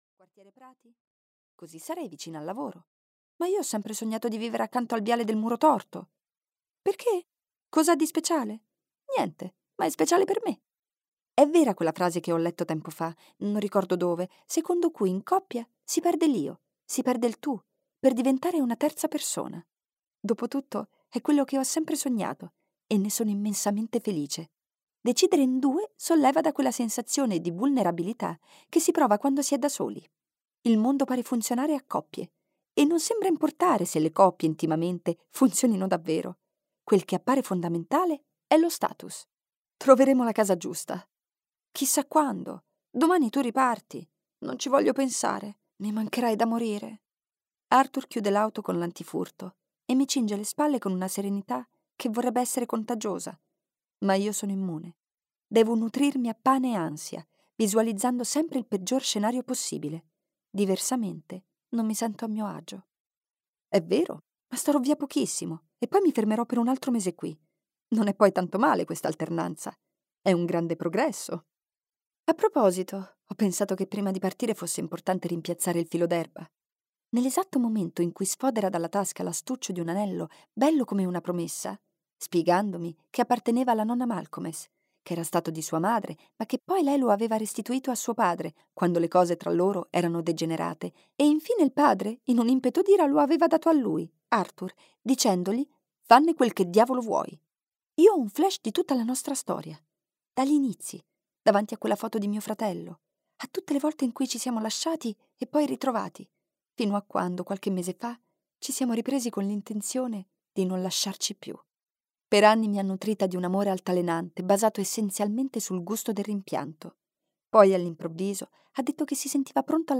"Un po' di follia in primavera" di Alessia Gazzola - Audiolibro digitale - AUDIOLIBRI LIQUIDI - Il Libraio